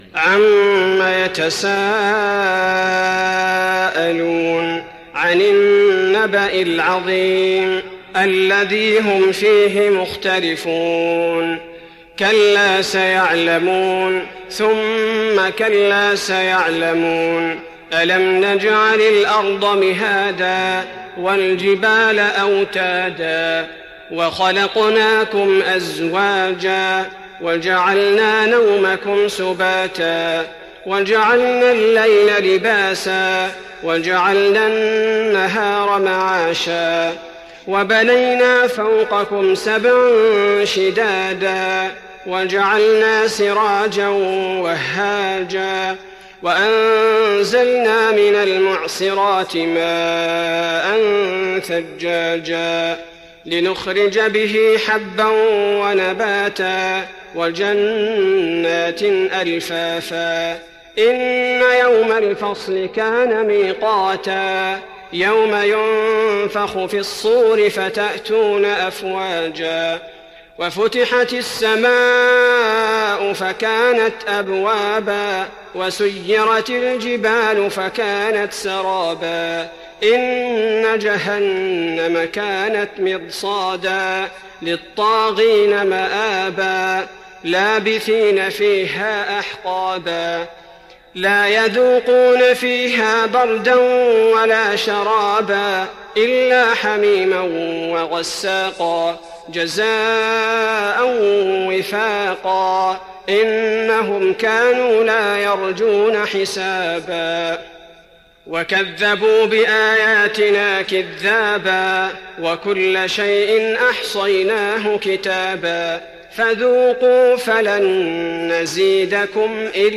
تراويح رمضان 1415هـ من سورة النبأ الى سورة الطارق Taraweeh Ramadan 1415H from Surah An-Naba to Surah At-Taariq > تراويح الحرم النبوي عام 1415 🕌 > التراويح - تلاوات الحرمين